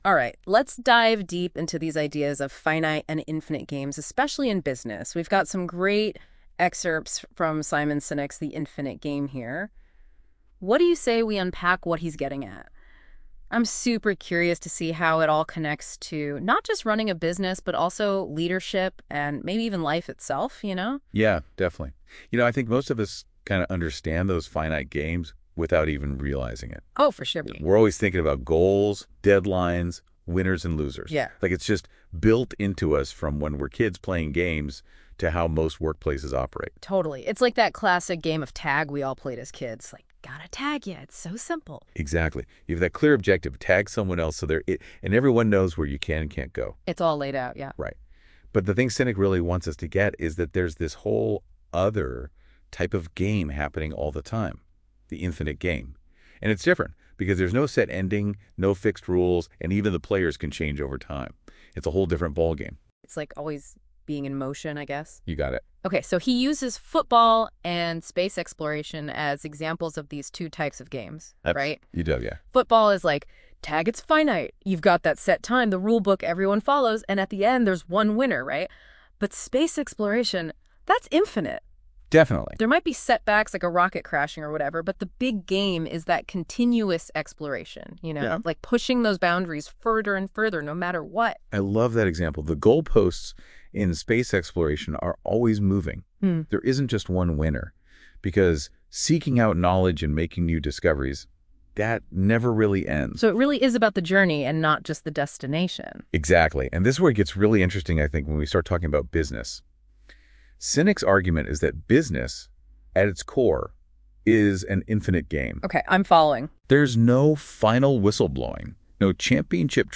הקשיבו לבינה מלאכותית מדברת על זה בצורה שנשמעת מאד מאד אנושית.